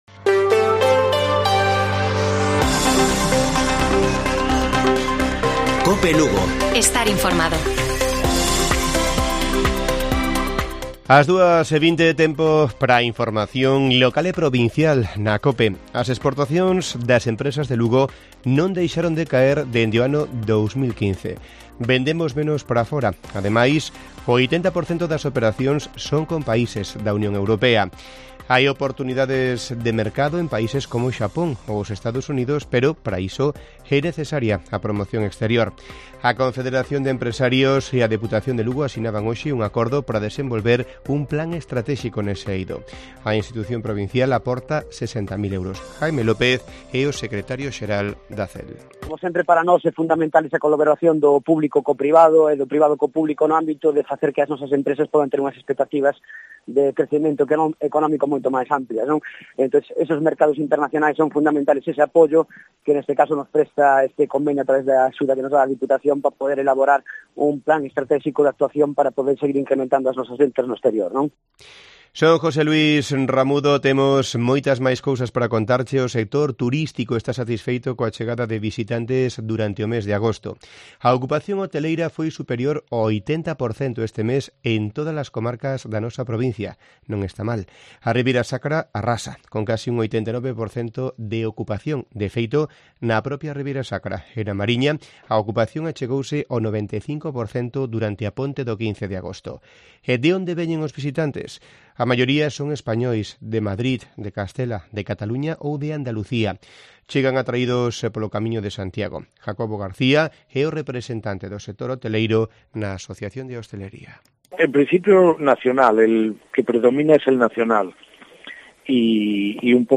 Informativo Mediodía de Cope Lugo. 31 DE AGOSTO. 14:20 horas